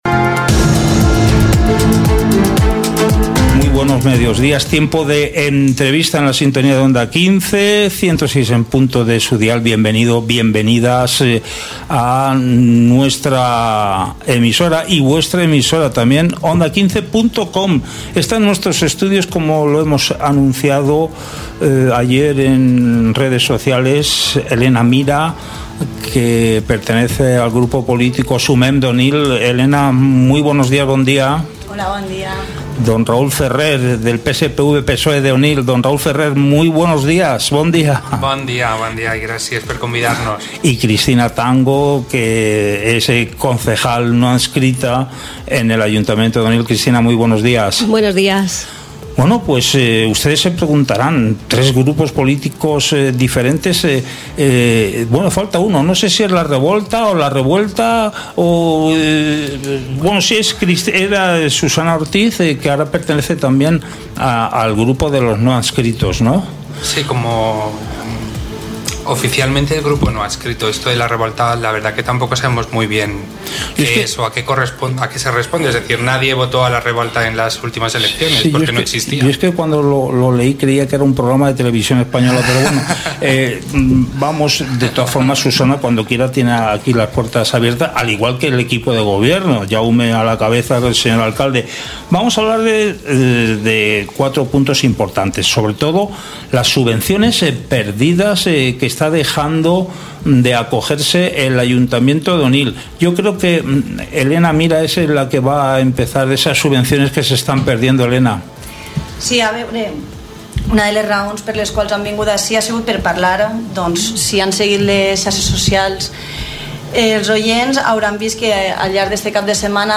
Entrevista a Raúl Ferrer, Concejal del PSPV-PSOE en Onil Helena Mira, Concejala de Sumem en Onil y Cristina Tango, Concejala no adscrita en Onil - Onda 15 Castalla 106.0 FM
Hoy en nuestro informativo hablamos con Raúl Ferrer, Concejal del (PSPV-PSOE) en Onil, Helena Mira, Concejala de (Sumem) en Onil y Cristina Tango (Concejala no adscrita), en Onil sobre la situación política en consistorio , en la entrevista repasamos los siguientes puntos :